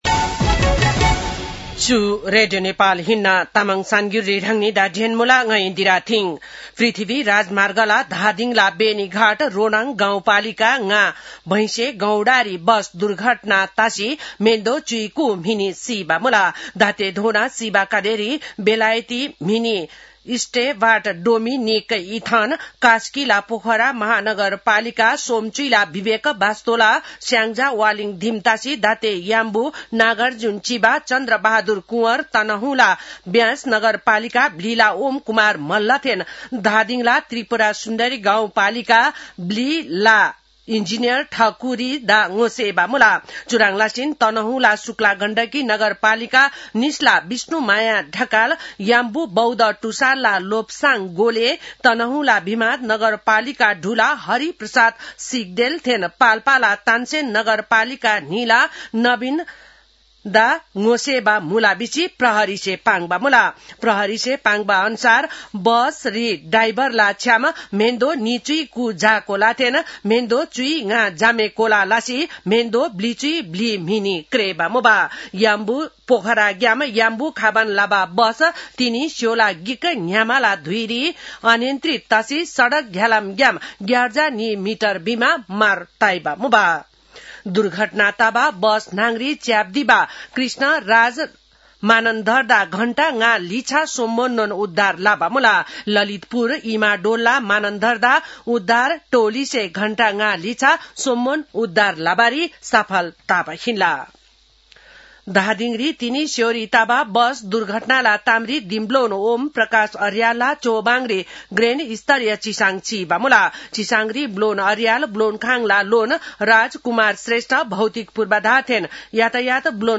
तामाङ भाषाको समाचार : ११ फागुन , २०८२
Tamang-news-11-11.mp3